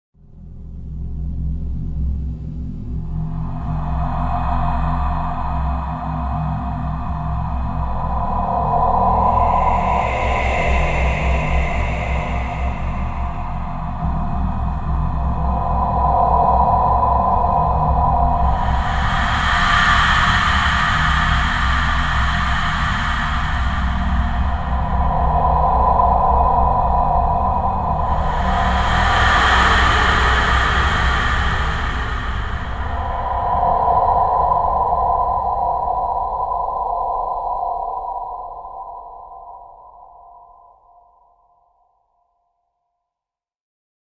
دانلود آهنگ شبح از افکت صوتی انسان و موجودات زنده
جلوه های صوتی
دانلود صدای شبح از ساعد نیوز با لینک مستقیم و کیفیت بالا
برچسب: دانلود آهنگ های افکت صوتی انسان و موجودات زنده دانلود آلبوم صدای حرف زدن ترسناک از افکت صوتی انسان و موجودات زنده